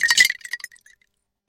Ice Cubes Clinking
Ice cubes clinking and shifting in a glass with bright, crystalline impacts
ice-cubes-clinking.mp3